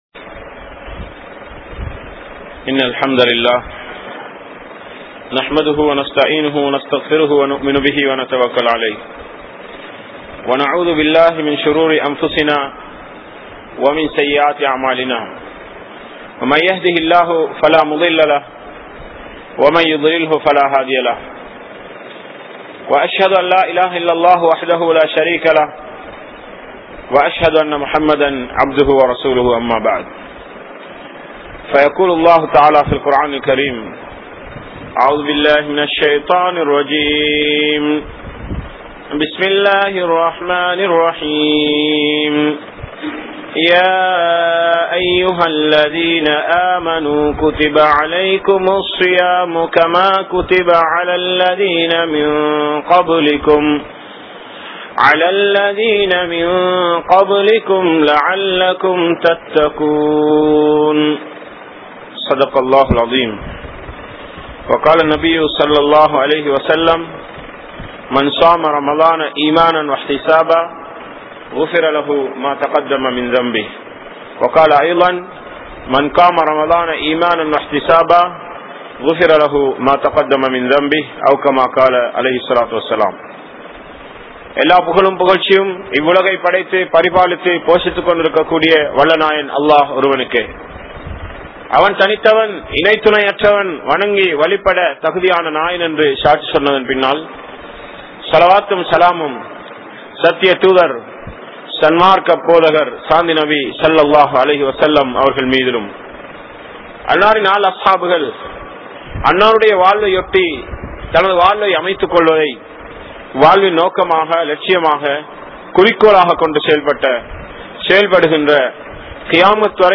Are You Ready For Ramalan? | Audio Bayans | All Ceylon Muslim Youth Community | Addalaichenai